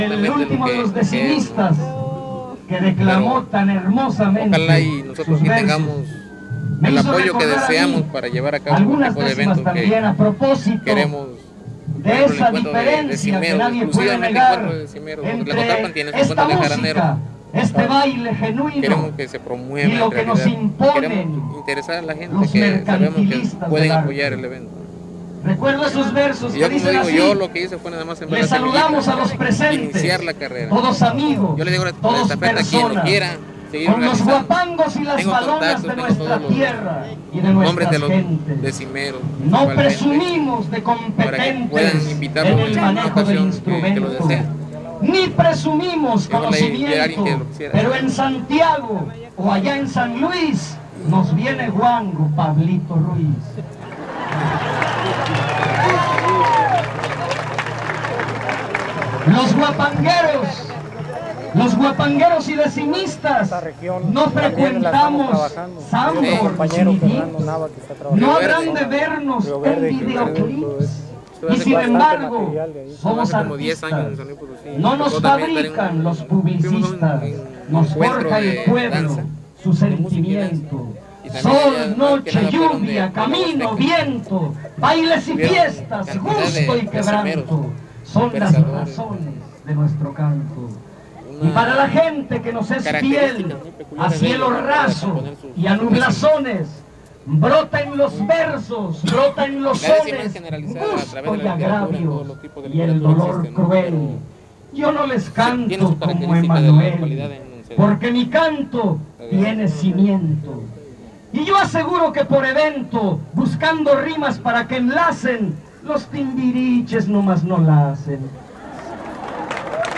• Leones de la Sierra (Grupo musical)
Encuentro de son y huapango